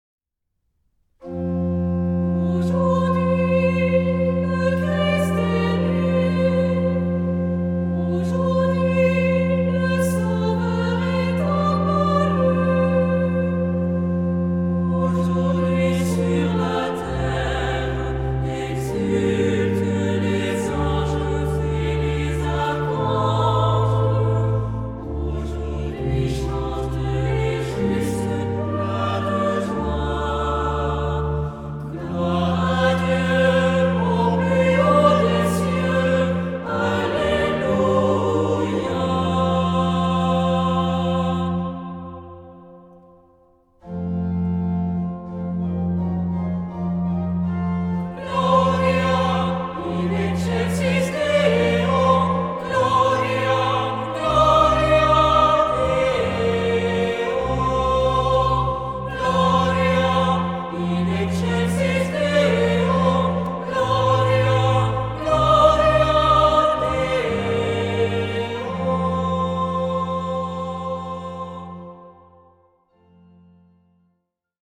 Noël
Genre-Stil-Form: Tropar ; Psalmodie
Charakter des Stückes: andächtig
Chorgattung: SAH ODER SATB  (4 gemischter Chor Stimmen )
Instrumente: Orgel (1) ; Melodieinstrument (ad lib)
Tonart(en): F-Dur